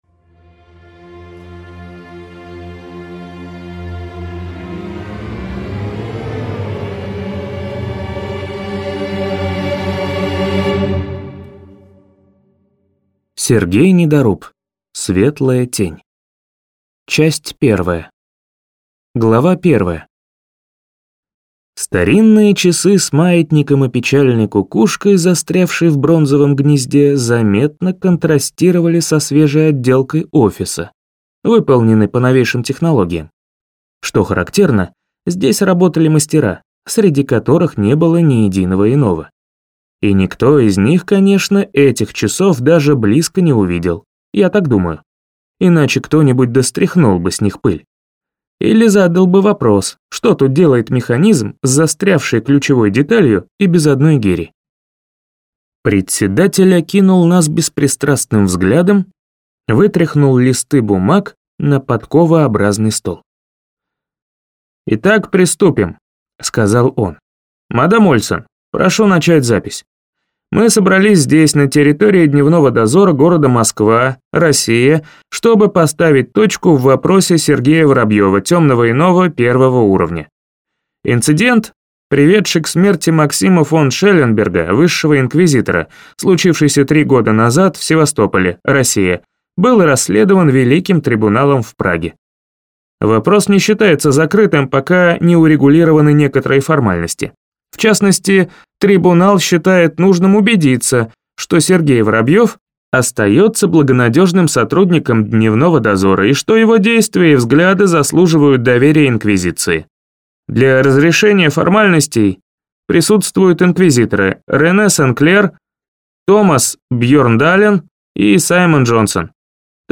Аудиокнига Светлая Тень | Библиотека аудиокниг